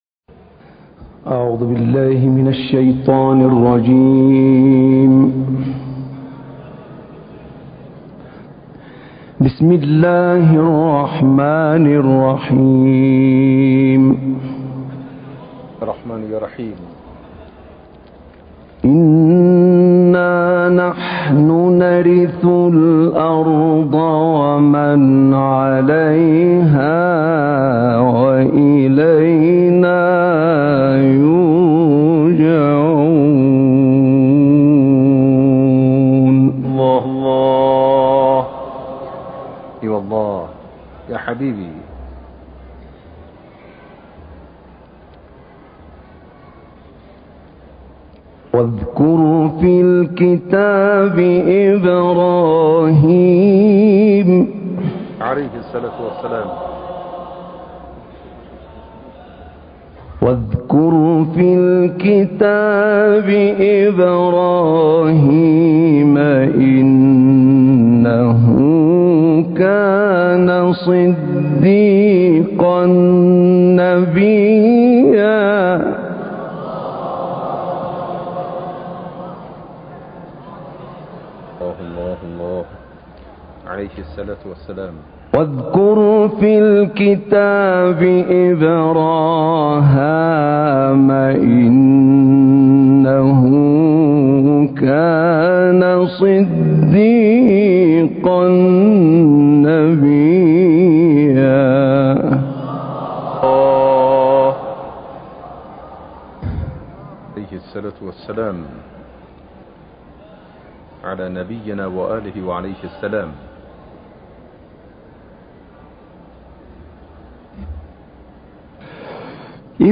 گروه شبکه اجتماعی: تلاوت احمد نعینع که در سال 1379 در سالن اجلاس سران کشورهای اسلامی اجرا شده است، می‌شنوید.
این تلاوت در سال 1379 در ایران و در سالن اجلاس سران کشورهای اسلامی انجام شده است.